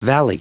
Prononciation du mot valley en anglais (fichier audio)
Prononciation du mot : valley